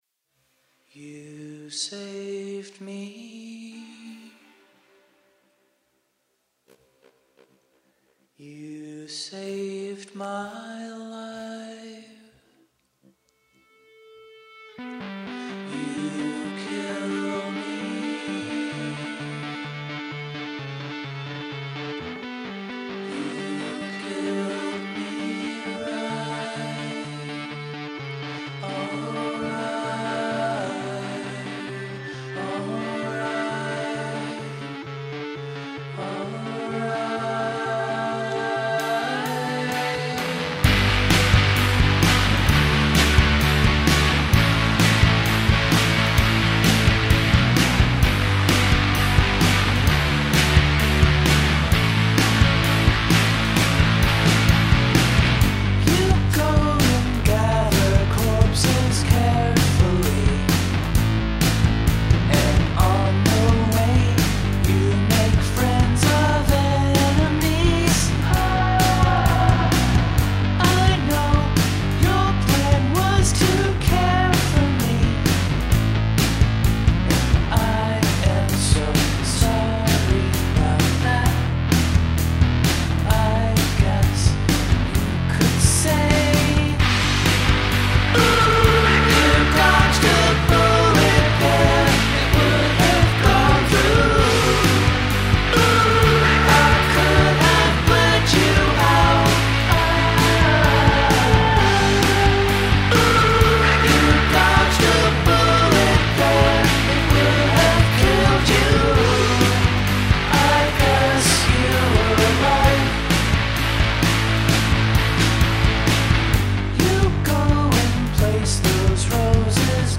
First, the crunchy rock song: